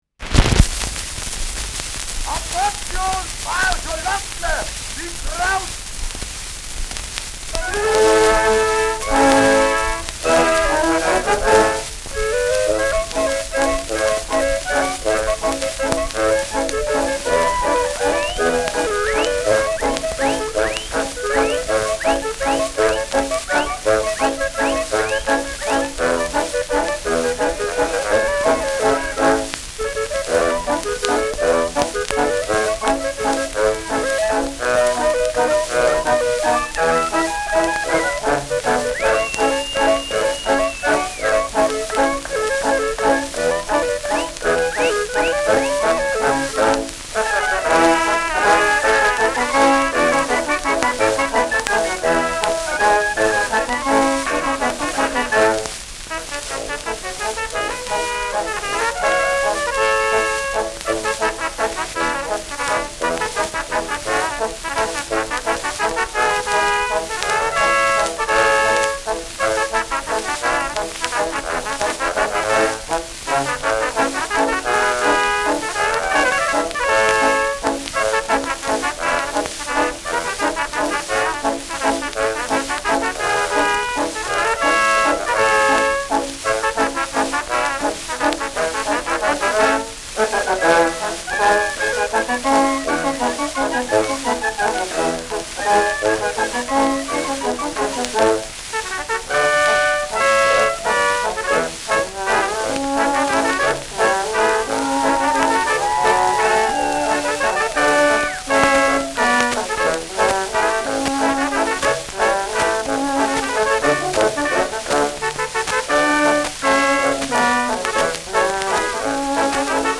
Schellackplatte
Abgespielt : Starkes Grundrauschen : Durchgehend leichtes Knacken
[unbekanntes Ensemble] (Interpretation)